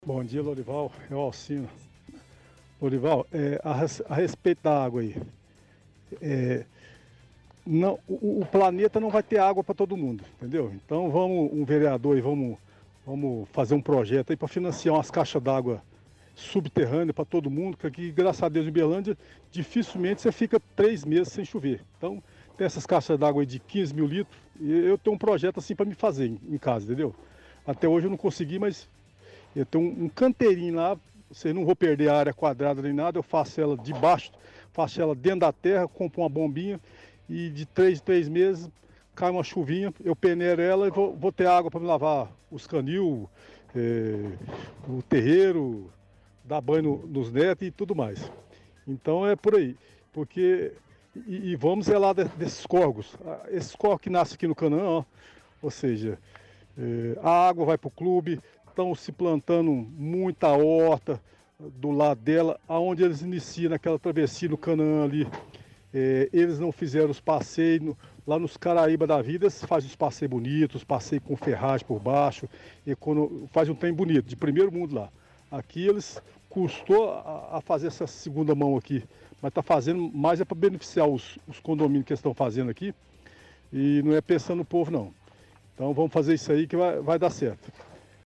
– Ouvinte pede que vereadores financiem projetos para caixas subterrâneas.